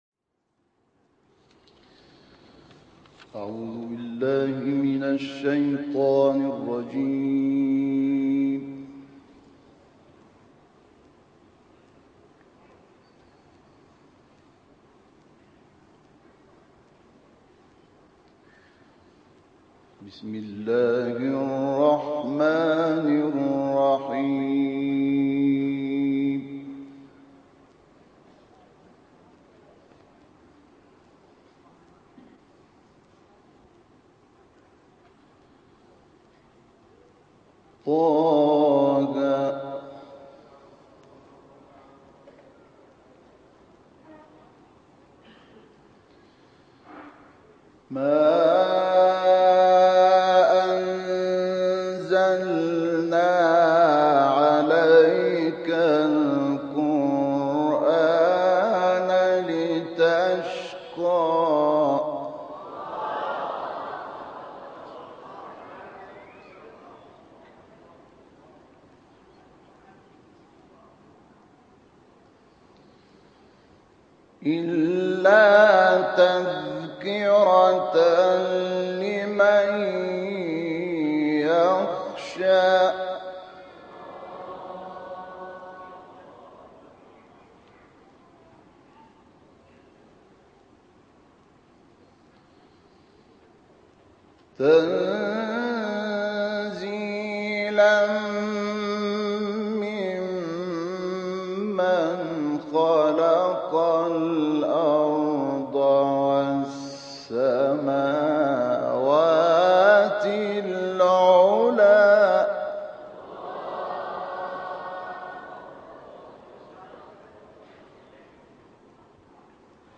سوره طه با تلاوت مرحوم شحات محمد انور+ دانلود/ شمردن ثمرات توحید و عواقب شرک
گروه فعالیت‌های قرآنی: شاهکاری از تلاوت استاد مرحوم شحات محمد انور از سوره طه، آیات ۱ تا ۵۲ ارائه می‌شود.